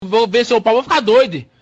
Una frase viral de portugués brasileño que significa "imagínate en pantalones cortos." Popular en TikTok como sonido de reacción cómico.